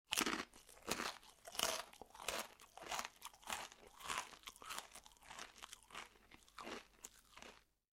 crunch2.wav